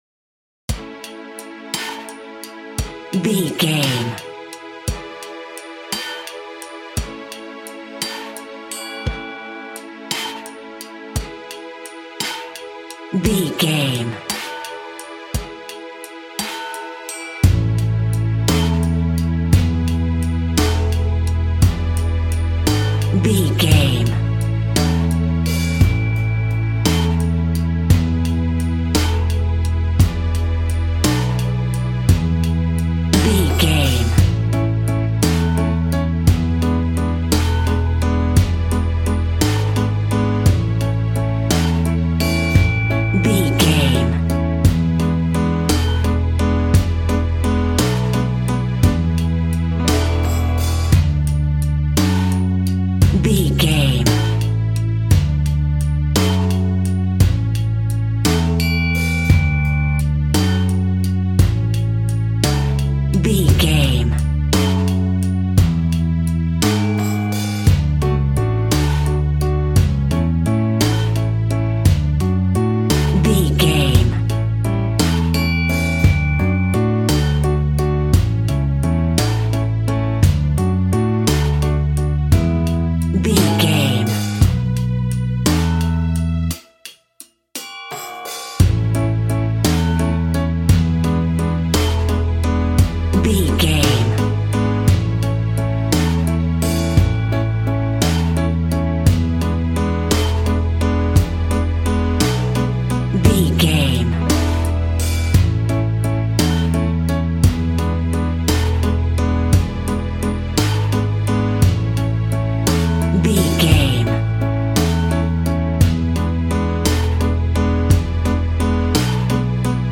Ionian/Major
kids instrumentals
kids library music
fun
childlike
cute
happy
kids piano